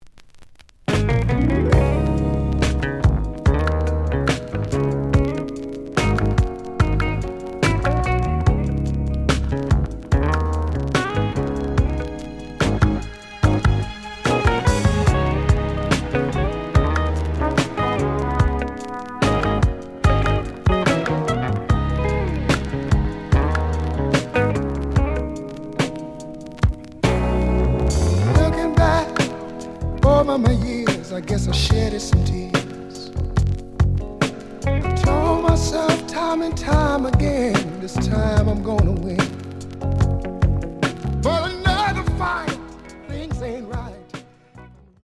The audio sample is recorded from the actual item.
●Genre: Soul, 80's / 90's Soul
Looks good, but slight noise on A side.